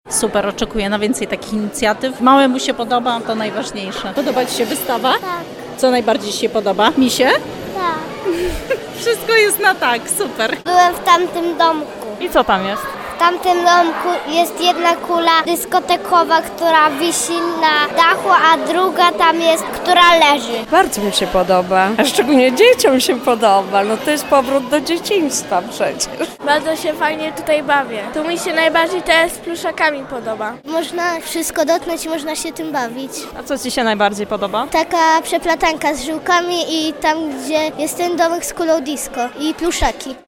zapytała zarówno dzieci jak i rodziców co sądzą o tej inicjatywie: